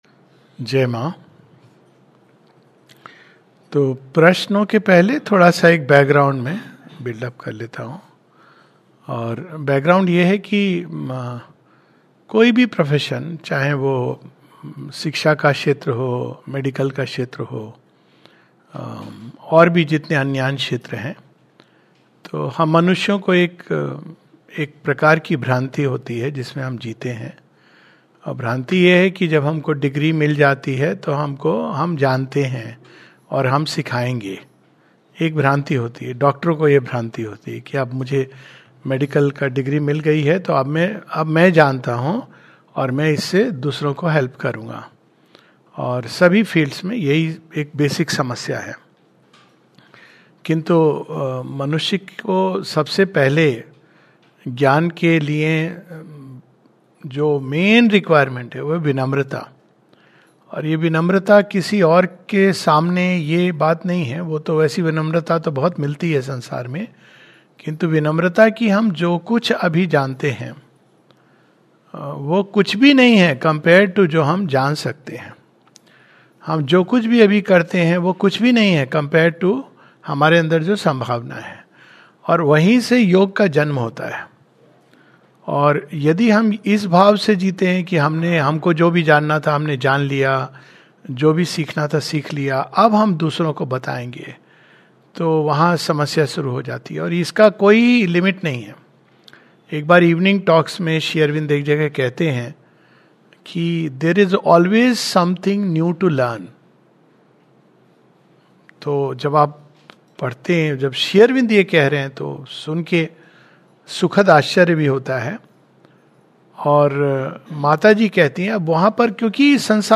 [National Education]. A talk